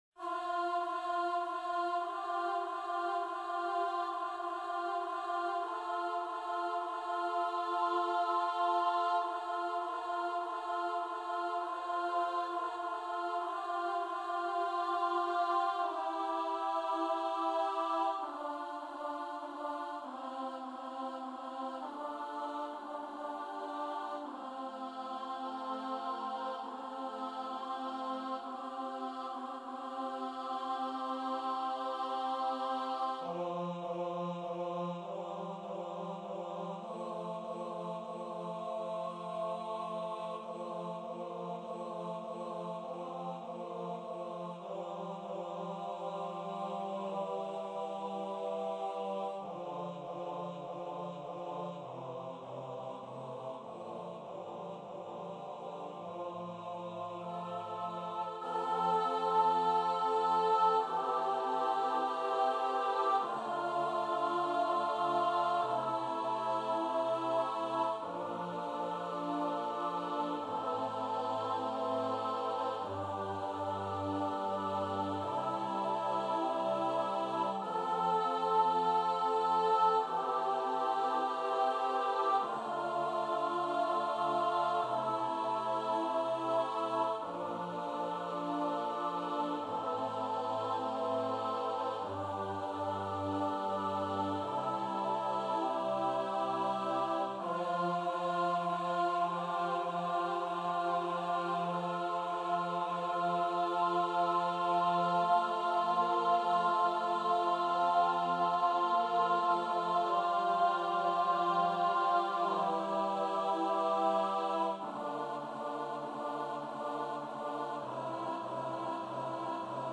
- Œuvre pour choeur à 8 voix mixtes (SSAATTBB) a capella
MP3 rendu voix synth.
Alto 1